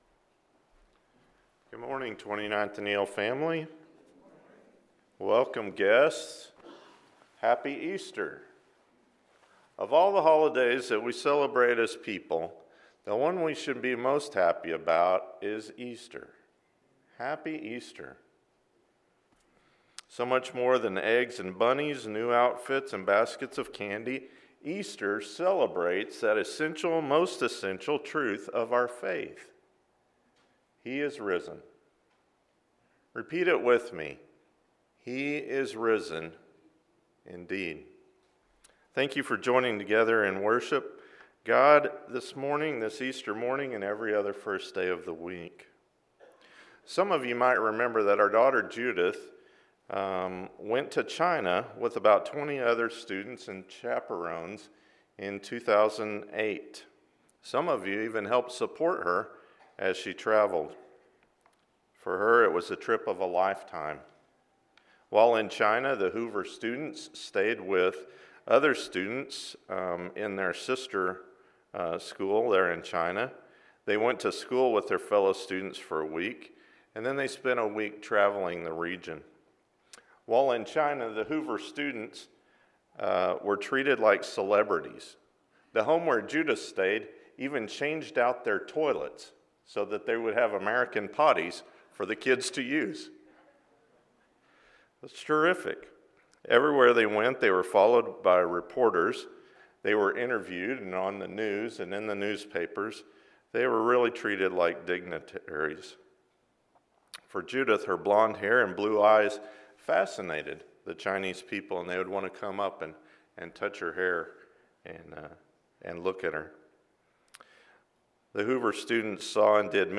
On the Road Together with the Apostles – Sermon